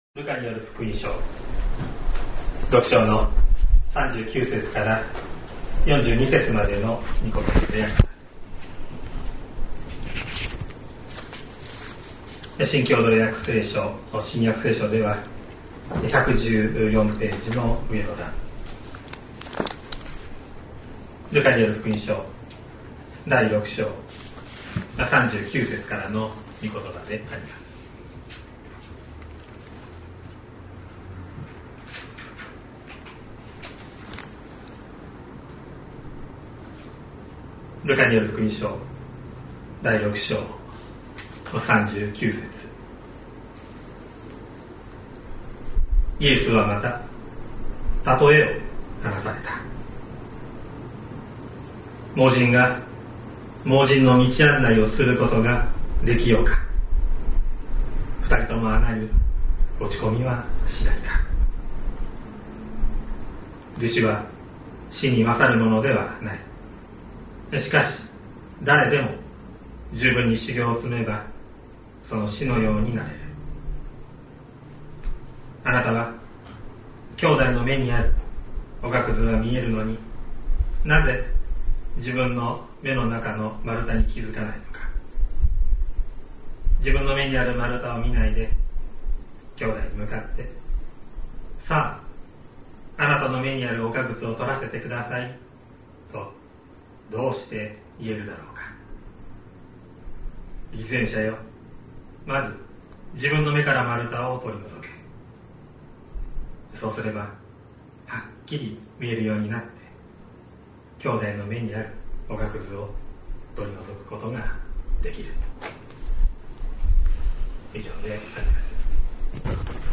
2023年08月13日朝の礼拝「自分の目」西谷教会
音声ファイル 礼拝説教を録音した音声ファイルを公開しています。